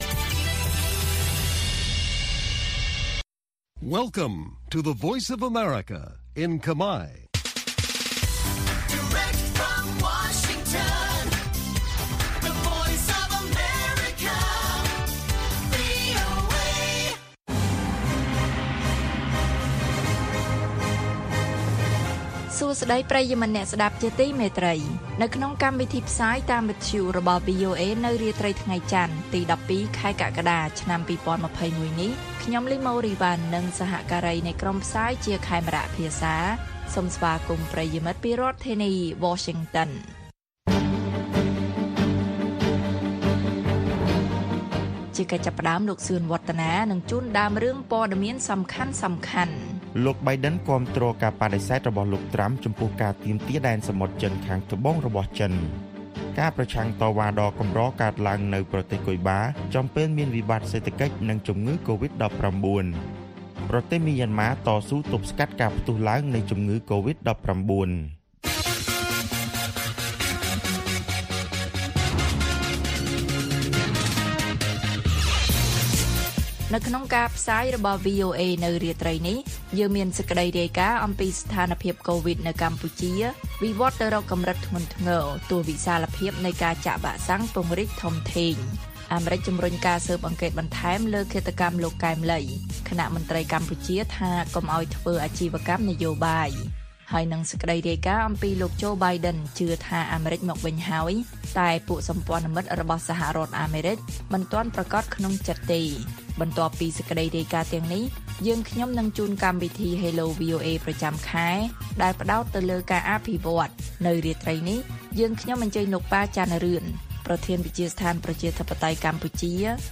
ព័ត៌មានពេលរាត្រី៖ ១២ កក្កដា ២០២១